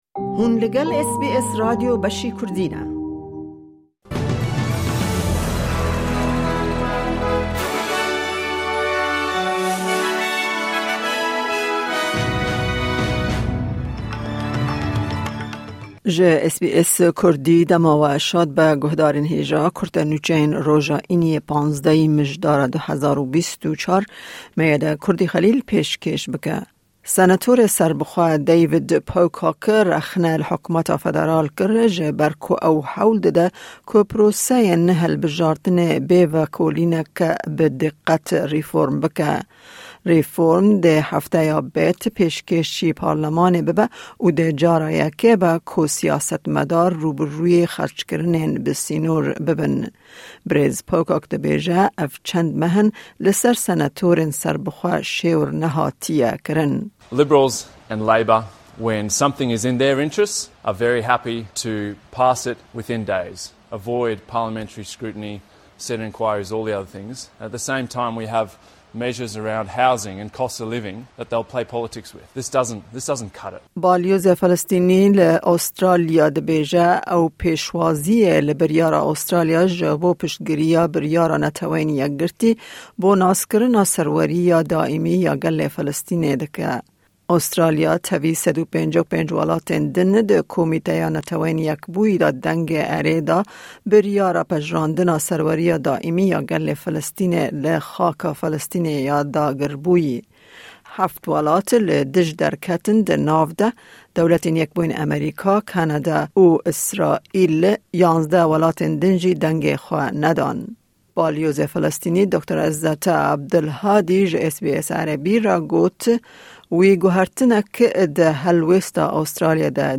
Kurte Nûçeyên roja Înî 15î Mijdara 2024